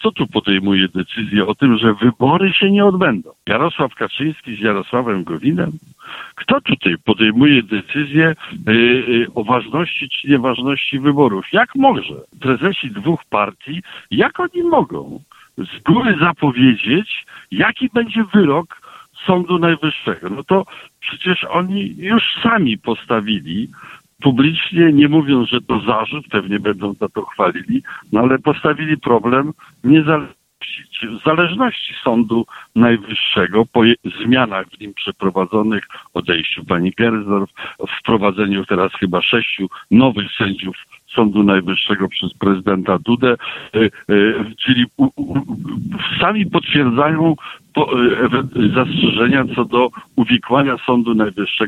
Bronisław Komorowski komentował decyzje dotyczące głosowania dziś (07.05), podczas audycji „Gość Radia 5”.